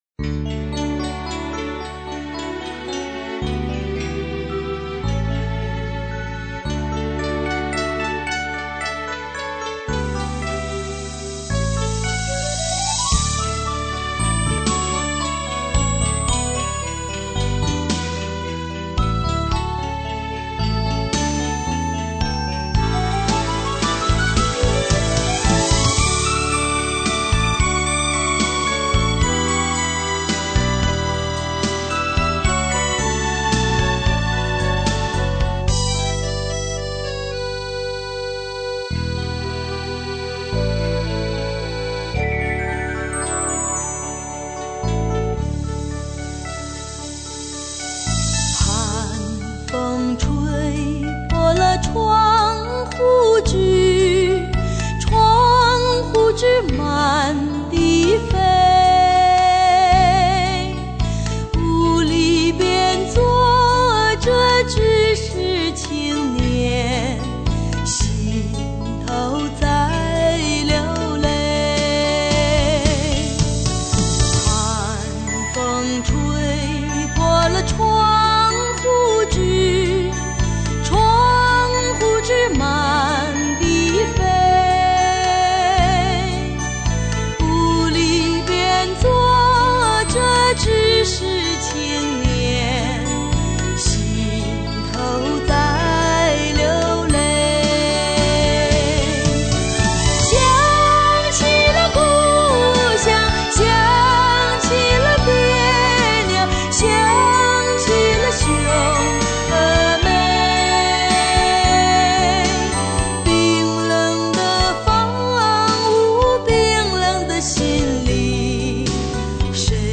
挺悲凉的一首歌曲!